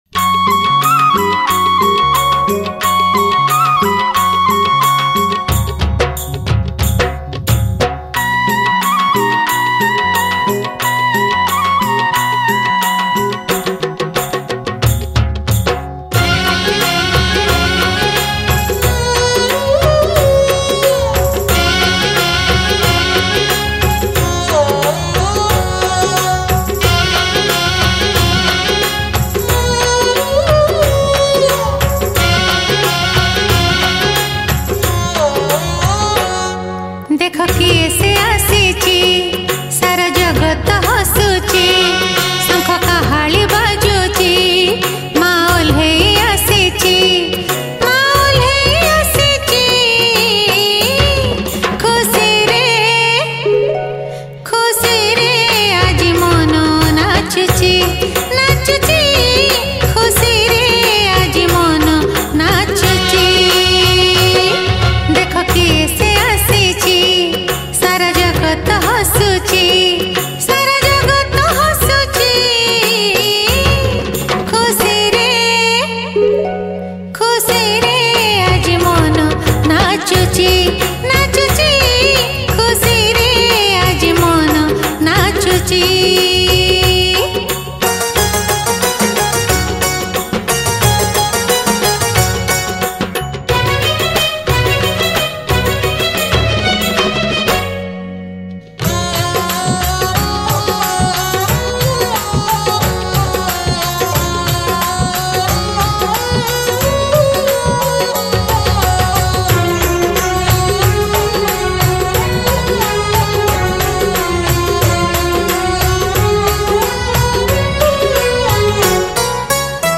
Durga Puja Special Song 2022 Songs Download